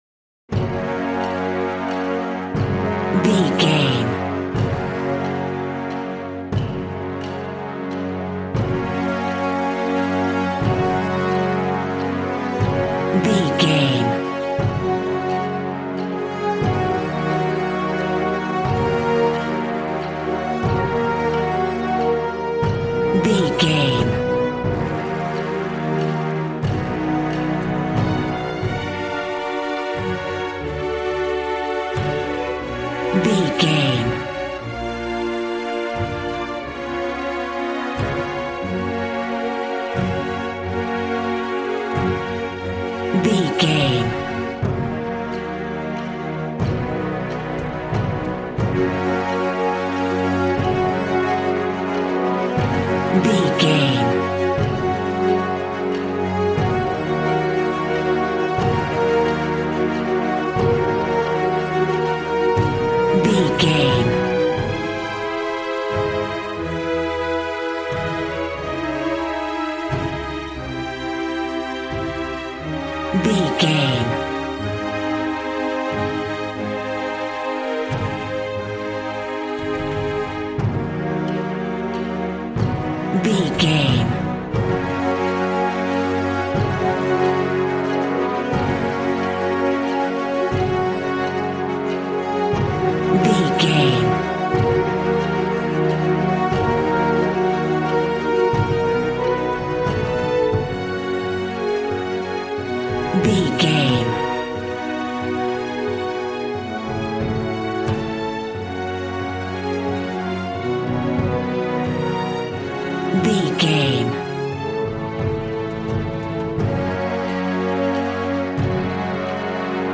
Ionian/Major
G♭
dramatic
strings
violin
brass